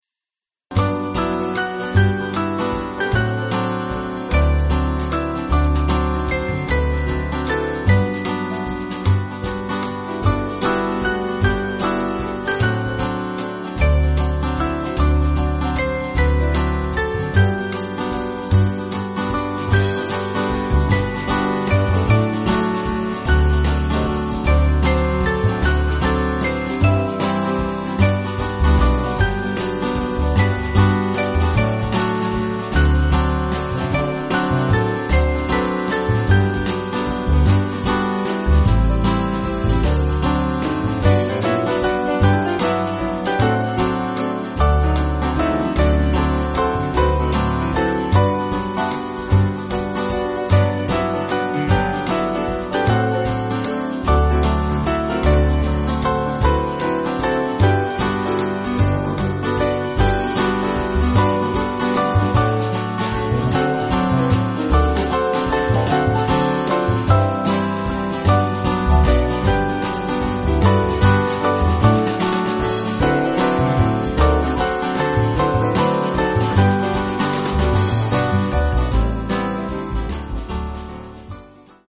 Swing Band
(CM)   4/G